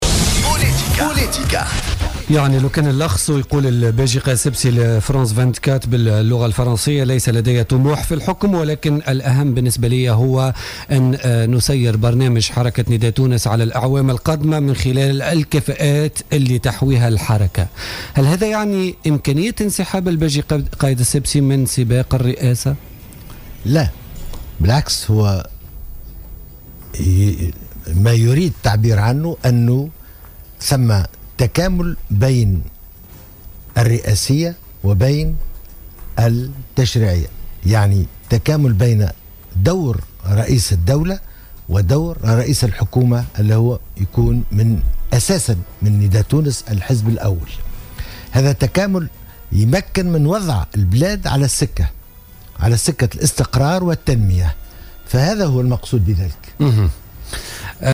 أكد الطيب البكوش،أمين عام حزب نداء تونس لدى استضافته اليوم في برنامج "بوليتيكا" أن رئيس الحزب الباجي قائد السبسي لن ينسحب من سباق الانتخابات الرئاسية.